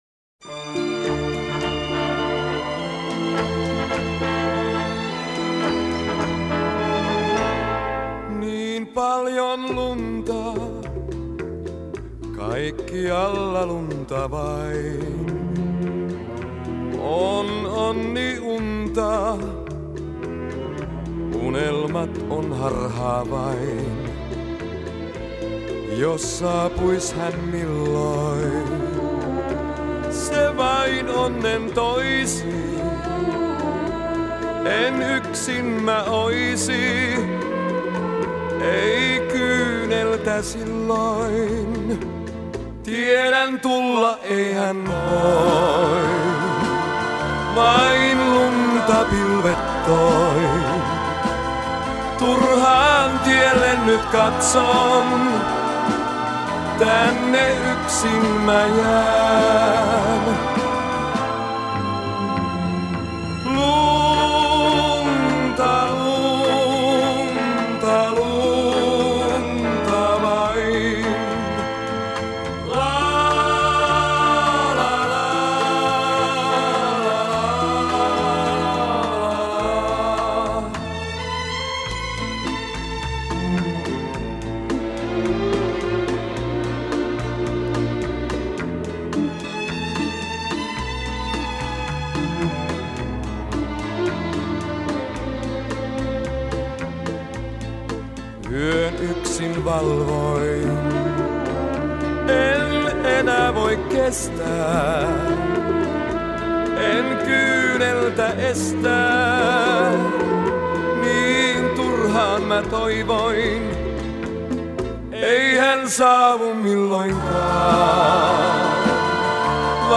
финская перепевка